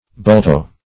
Bultow \Bul"tow`\, n.